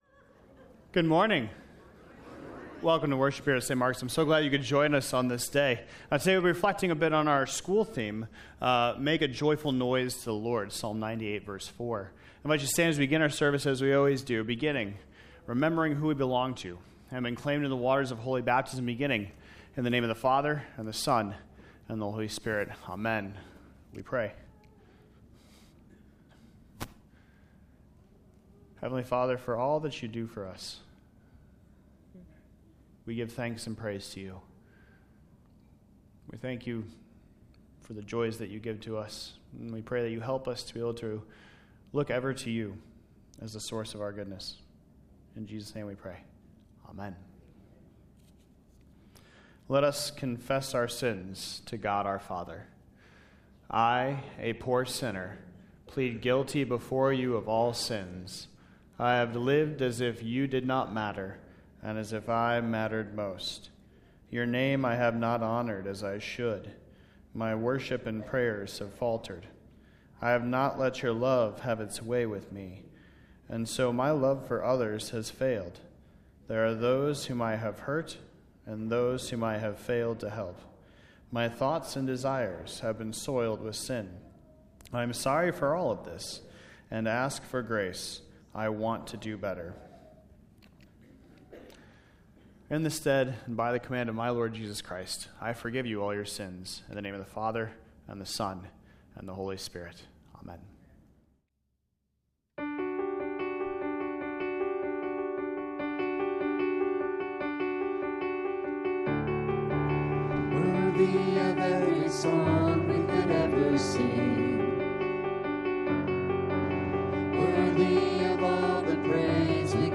2026-February-1-Complete-Service.mp3